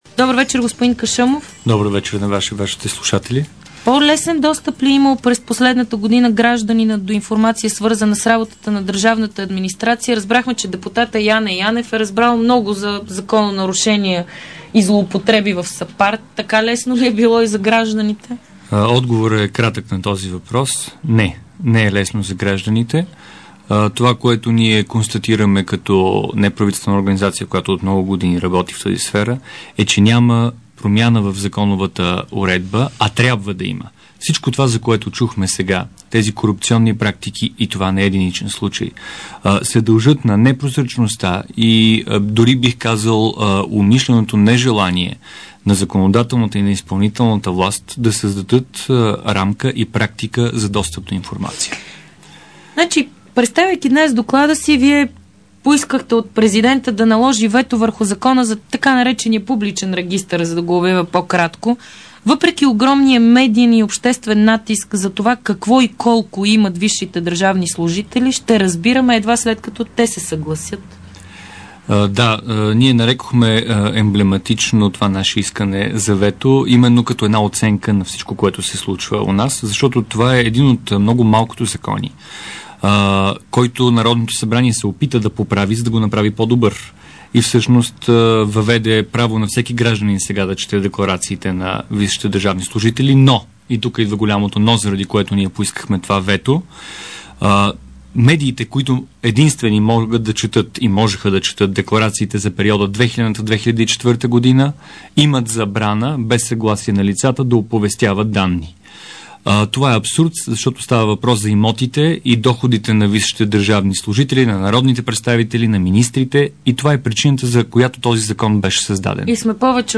DarikNews audio: Интервю